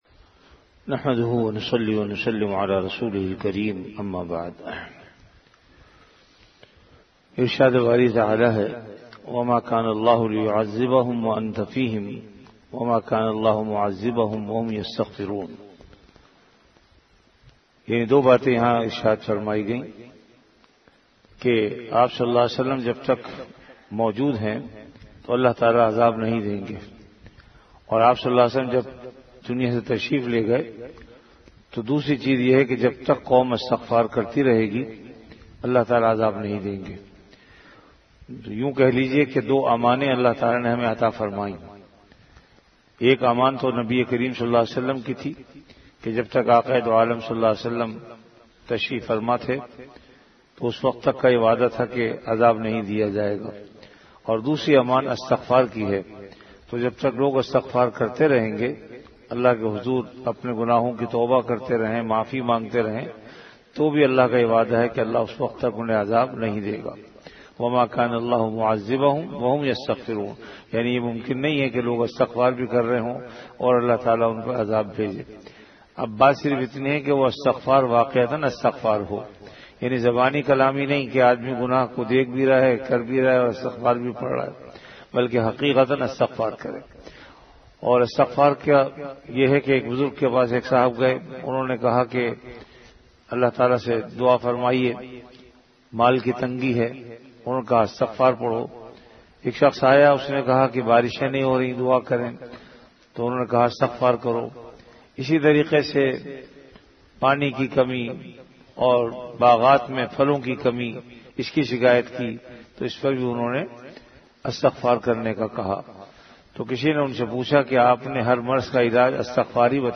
Delivered at Jamia Masjid Bait-ul-Mukkaram, Karachi.
Ramadan - Dars-e-Hadees · Jamia Masjid Bait-ul-Mukkaram, Karachi